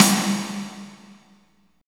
48.08 SNR.wav